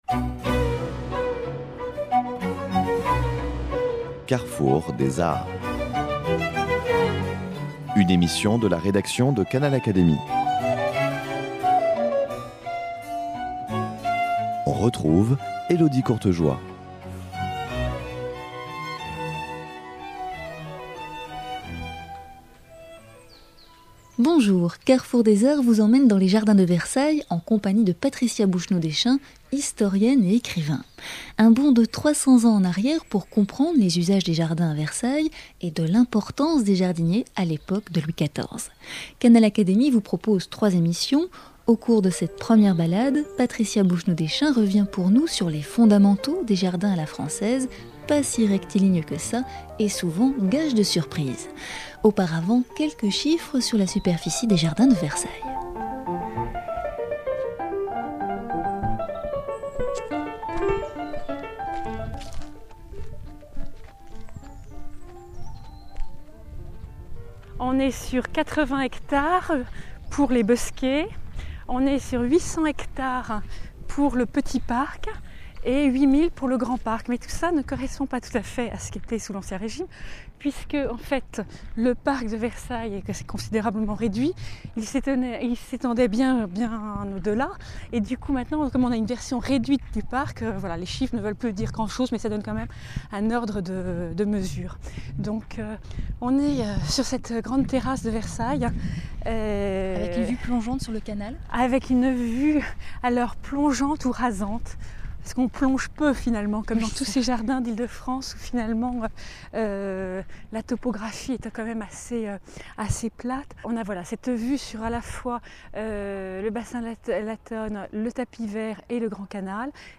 Sur place, découvrez les usages des jardins à Versailles et l’importance du métier de jardinier à l’époque de Louis XIV. Pour l’heure, dans ce premier volet, notre invitée revient sur les grands principes des jardins à la française.